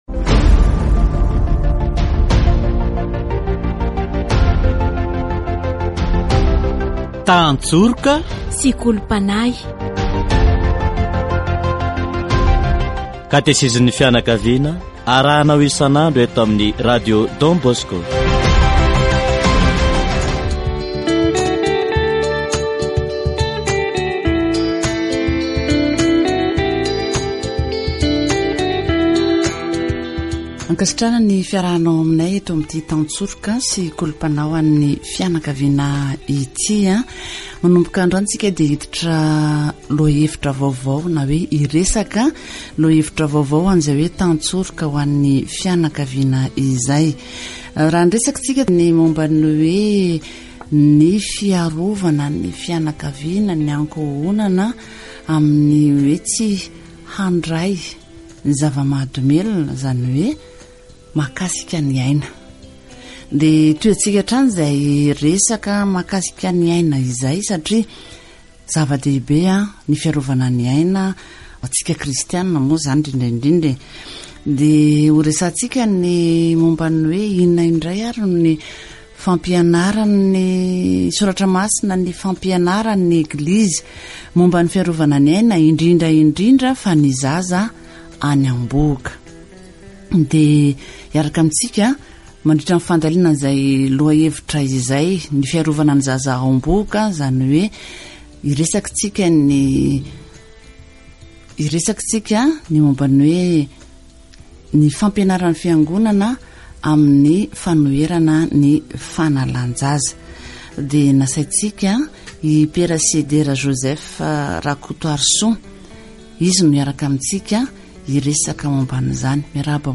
Catégorie : Approfondissement de la foi